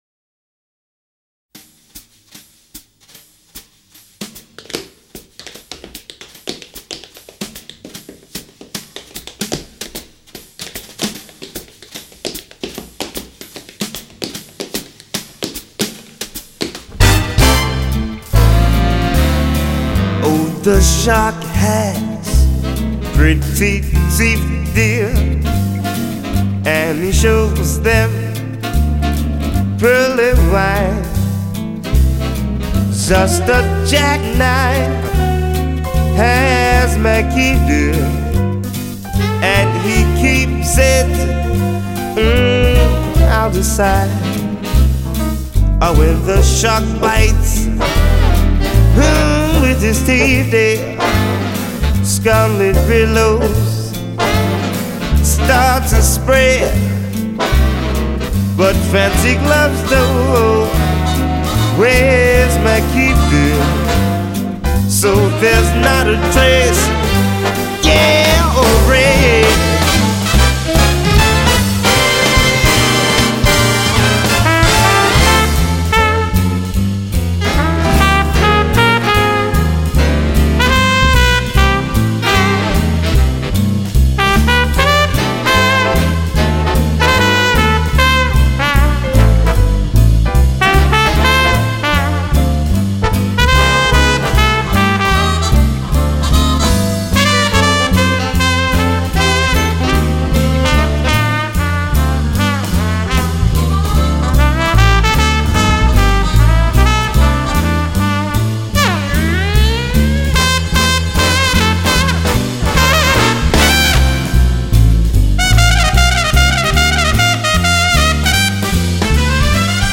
[light]獨特悠閒的音樂空間，36首最能掌握時代脈動的爵士樂精選 !